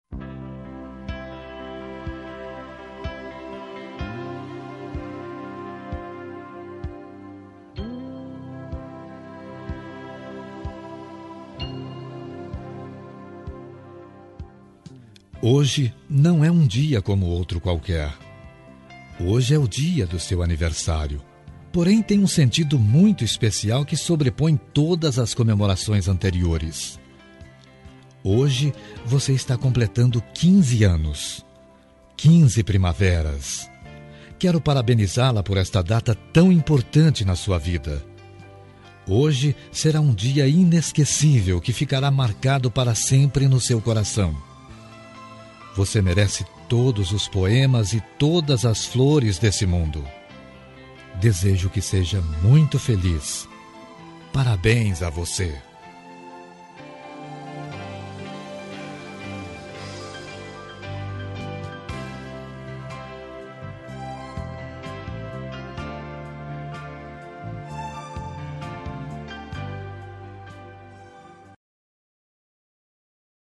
Aniversário de 15 Anos – Voz Masculina – Cód: 33384